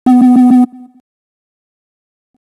buzz.RPnvh2n3.wav